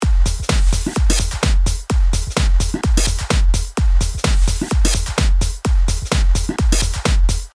• Diode：ダイオード（名称はちょっとピンと来なかった）Tubeよりちょっとソリッドな感じ？
ドライブレベル50％：Tubeのときほどハッキリはわからない感じにきこえるのはオレだけでしょうか？